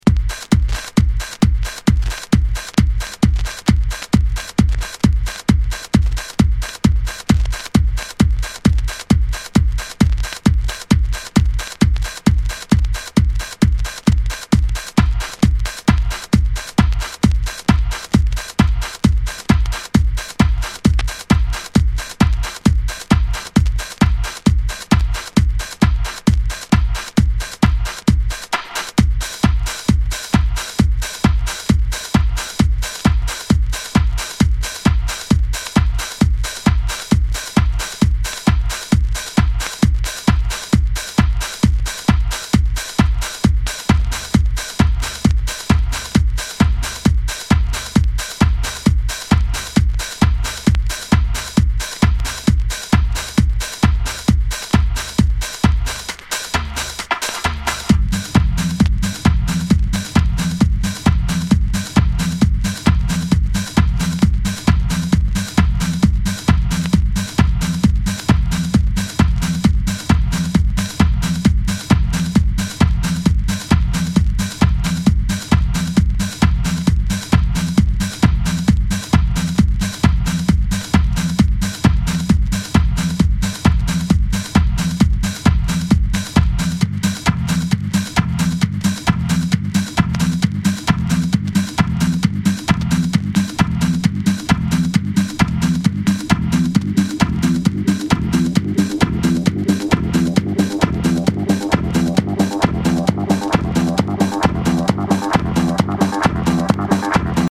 ハウス
高速ビートとベースラインで突き抜け、後半にはサックスも入ってピークタイムを彩る